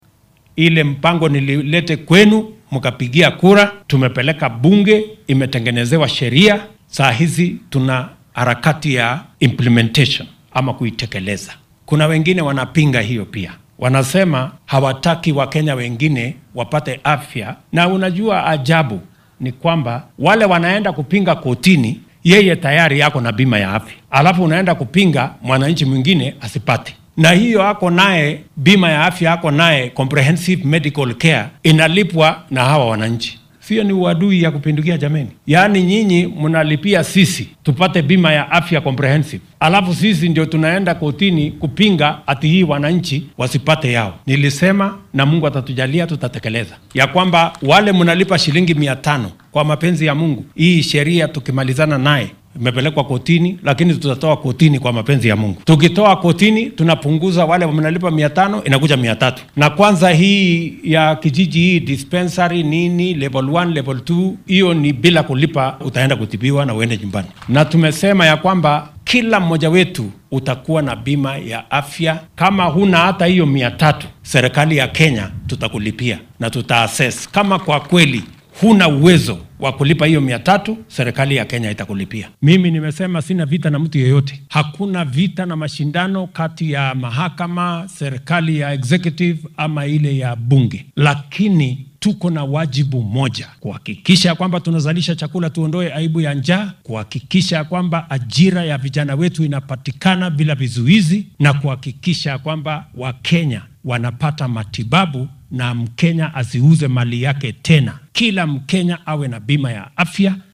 Madaxweynaha dalka William Ruto ayaa hoggaamiyaasha siyaasadeed ka codsaday inay maamulkiisa garab ku siiyaan dhaqangelinta mashaariicda horumarineed ee dhanka cunnada , shaqo abuurka iyo caafimaadka guud. Waxaa uu hoosta ka xarriiqay in haddii dowladdu aynan qaadan go’aammo adag in aan Kenya horumar la gaarsiin karin. Hoggaamiyaha dalka ayaa xilli uu ku sugnaa ismaamulka Uasin Gishu waxaa uu sheegay in uunan dagaal ka dhexeyn golaha fulinta ee dowladda , waaxda garsoorka iyo baarlamaanka.
William-Ruto-Uasin-Gishu.mp3